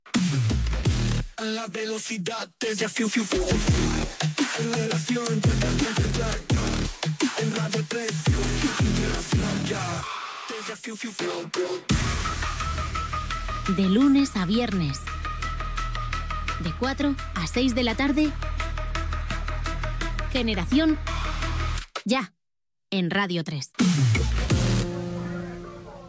Careta del programa.